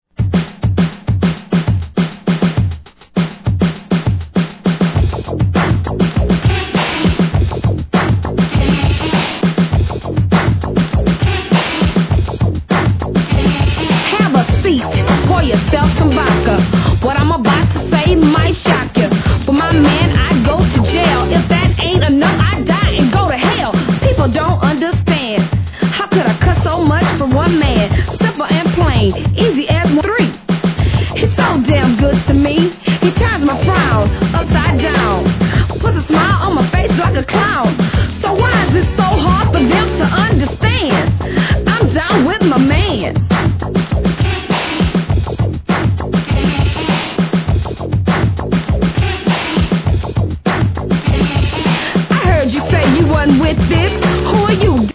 G-RAP/WEST COAST/SOUTH
初期のフィメールG! FUNKYミドル!!